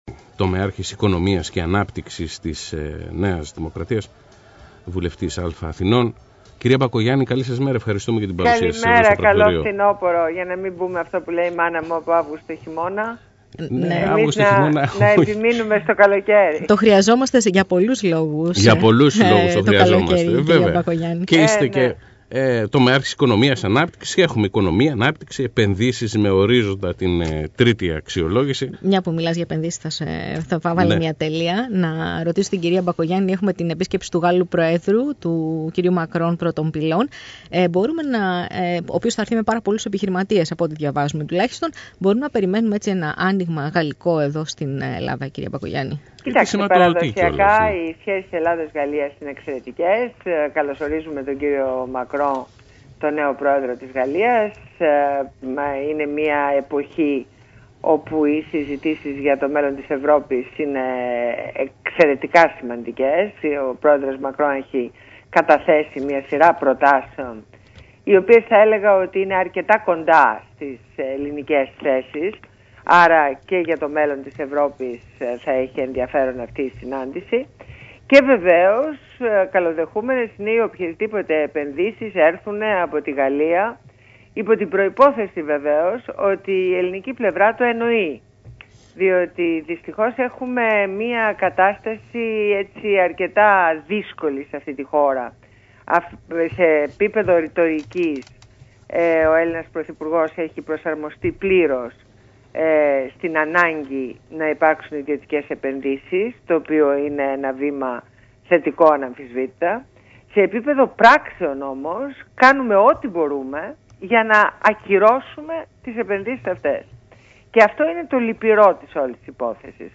Συνέντευξη στο ραδιόφωνο του ΑΠΕ - Πρακτορείο 104,9 Θεσσαλονίκη